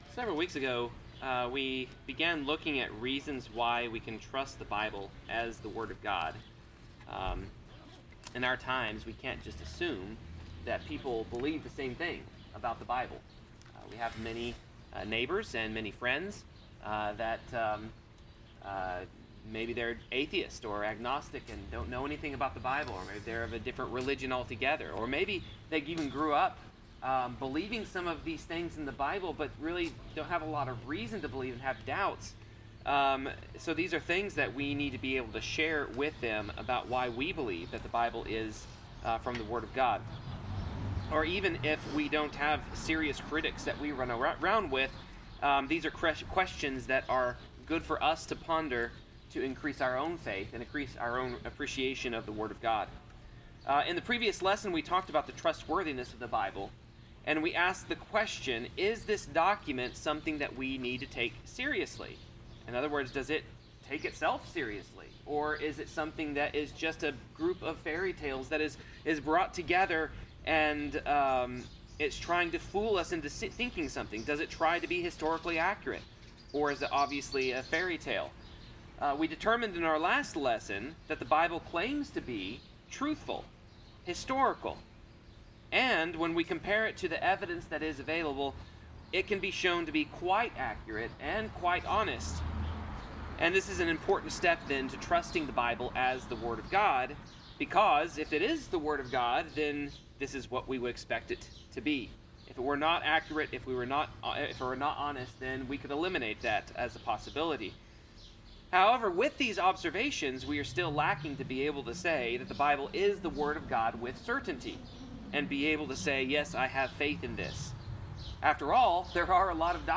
2 Timothy 3:16 Service Type: Sermon This week we continue to consider the Bible as the word of God.